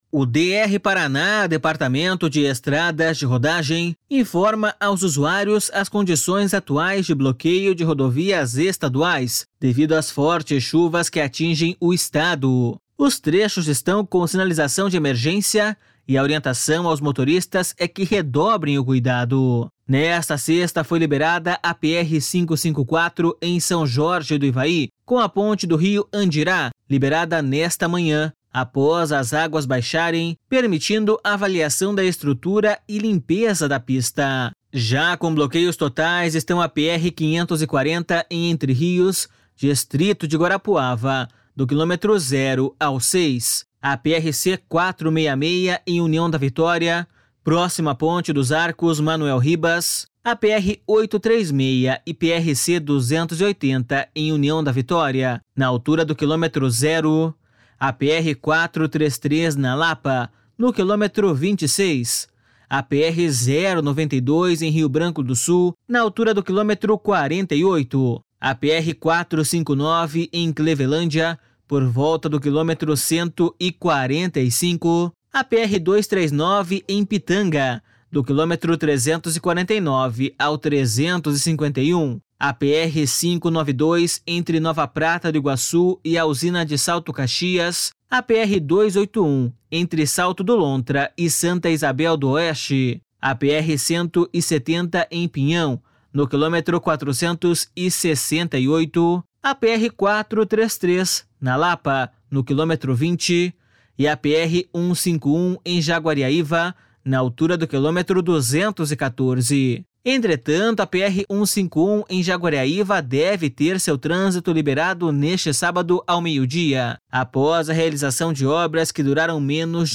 BOLETIM RODOVIAS ESTADUAIS 03-11.mp3